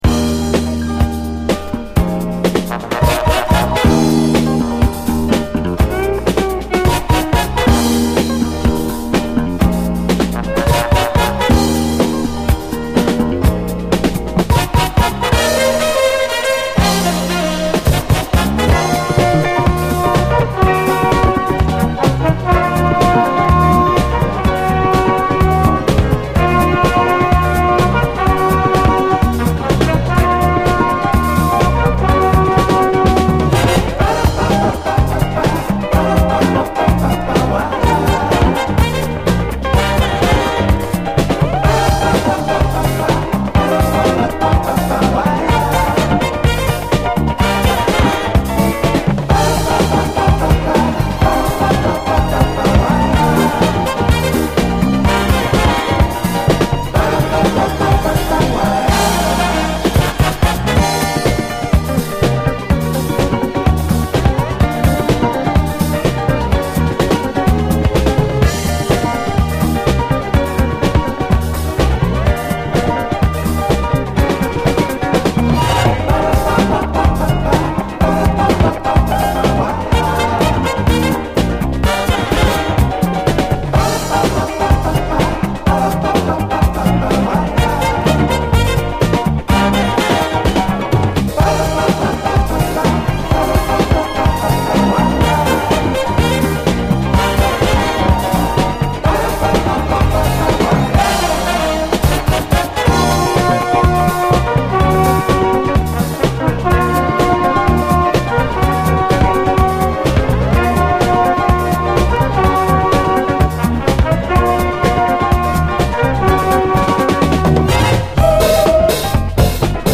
JAZZ FUNK / SOUL JAZZ, SOUL, 70's～ SOUL, JAZZ
パラッパ・スキャットとともに爽快に駆け抜ける！